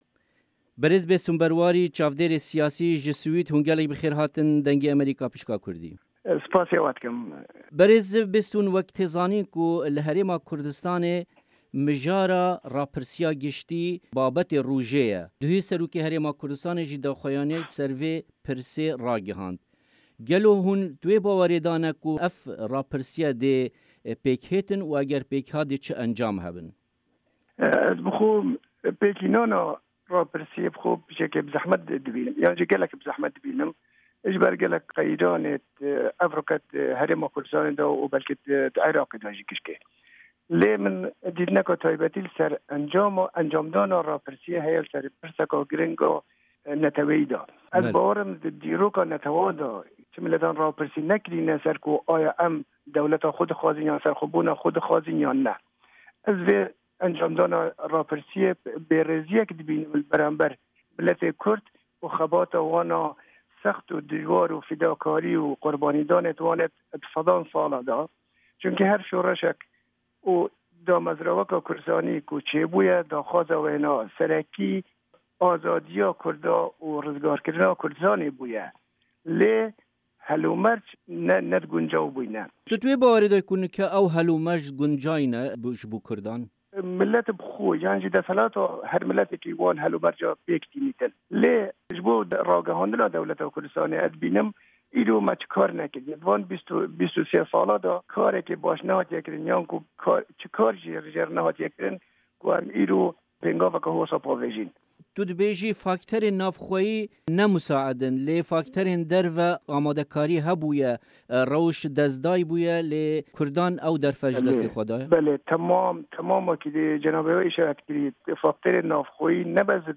Temamiya hevpeyvînê di fayla deng de ye.